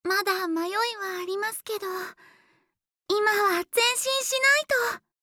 第三则配音片段：
甜美温柔的声线，一开口就勾勒出莎拉轻柔温暖的少女形象，感觉心都要融化了!